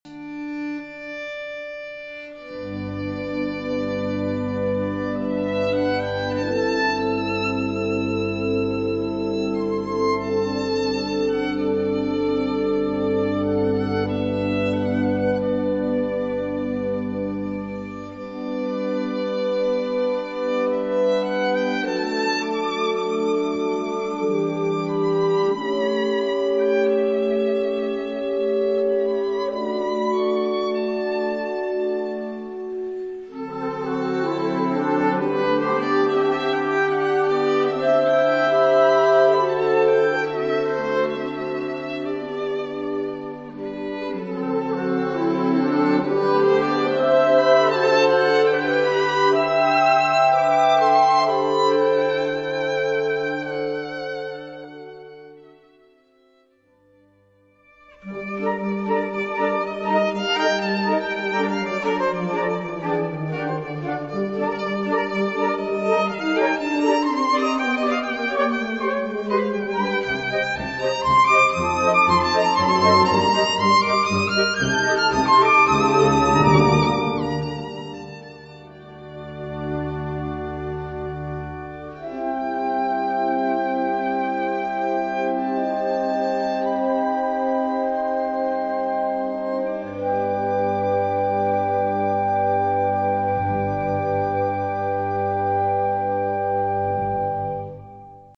Gattung: Solo für Violine
Besetzung: Blasorchester